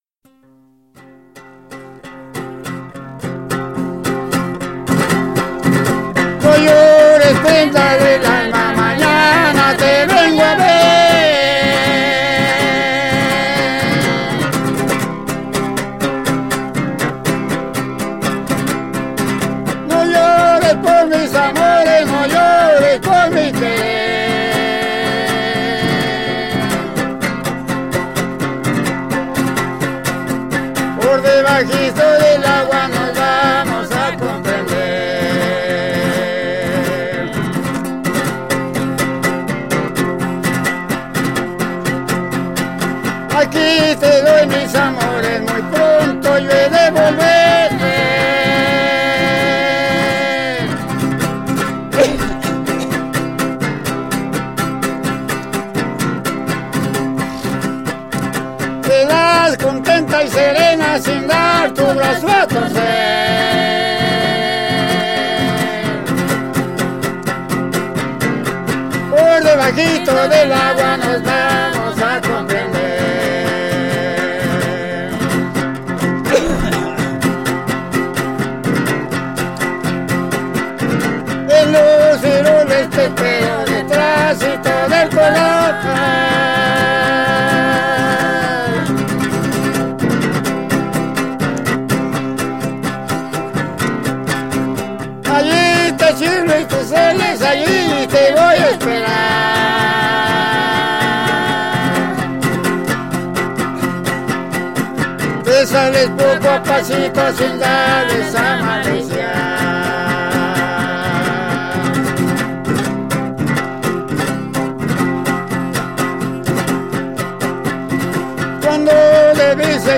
Keywords: arpa grande
folklor mexicano
Grabaciones de campo